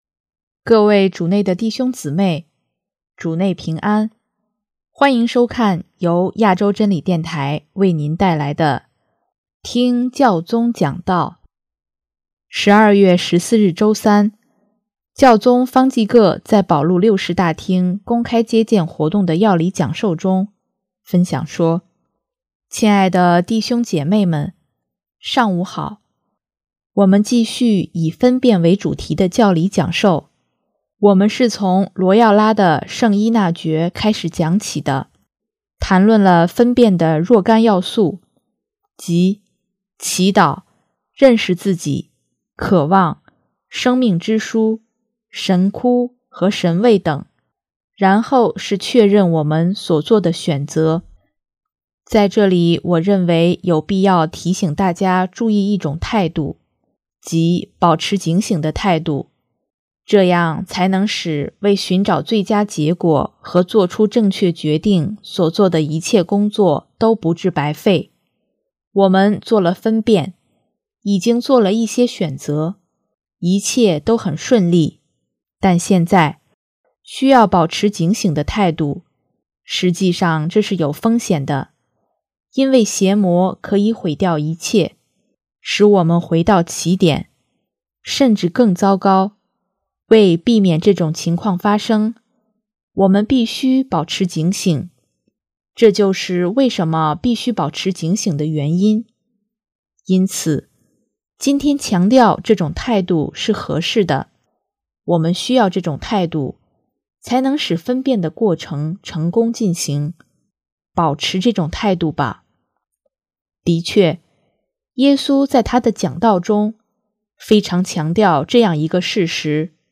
12月14日周三，教宗方济各在保禄六世大厅公开接见活动的教理讲授中，分享说：